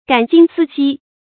感今思昔 注音： ㄍㄢˇ ㄐㄧㄣ ㄙㄧ ㄒㄧ 讀音讀法： 意思解釋： 見「感今懷昔」。